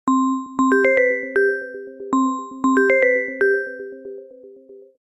7. Soft Bell Notification Tone
Soft bell sounds are perfect for people who want a calm and pleasant notification tone.
soft_bell_notification_tone.mp3